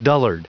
Prononciation du mot dullard en anglais (fichier audio)
Prononciation du mot : dullard